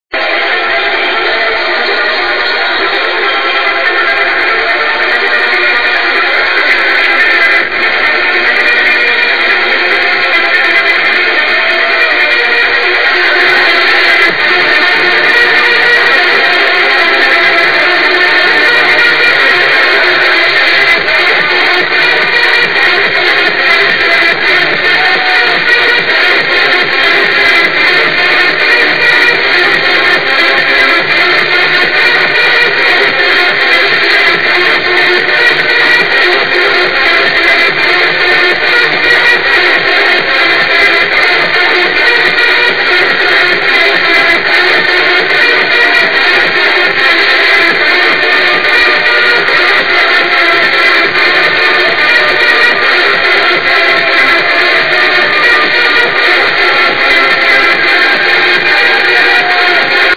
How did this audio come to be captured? hmmm very poor quality sample..